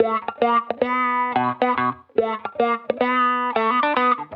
Index of /musicradar/sampled-funk-soul-samples/110bpm/Guitar
SSF_StratGuitarProc1_110B.wav